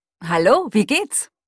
Im Folgenden noch drei Audio-Proben von der deutschen Cortana-Stimme. Wenn die wirklich echt ist und alle Sätze so klingen, ist die Stimme sogar etwas natürlicher als das Pendant von Apple.